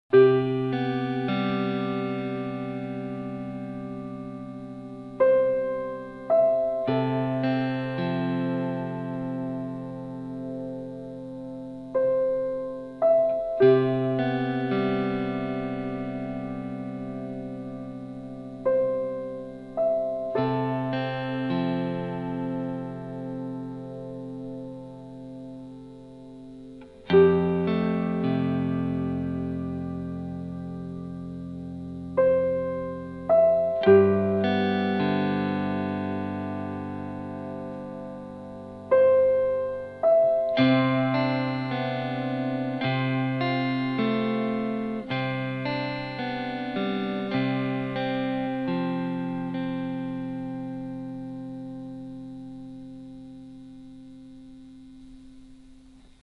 Recorded and mixed at Village Recorders